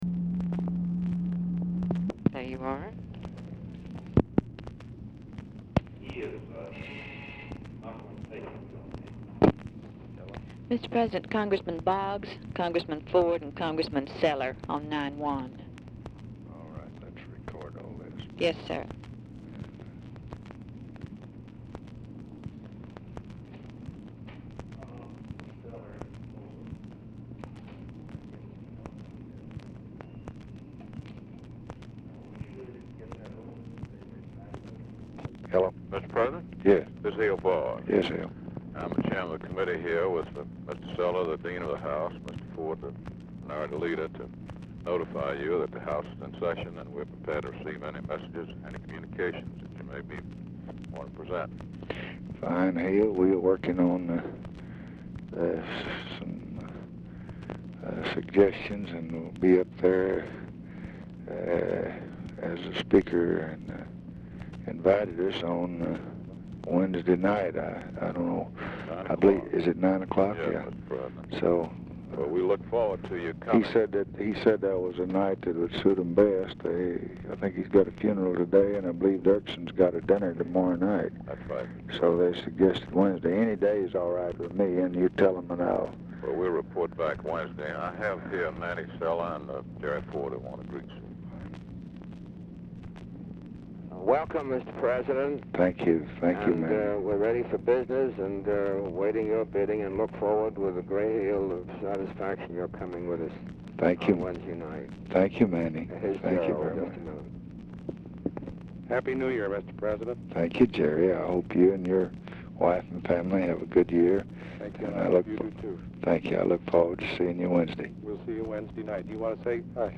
OFFICE SECRETARY; OFFICE CONVERSATION; EMANUEL CELLER; GERALD FORD
Oval Office or unknown location
Telephone conversation
Dictation belt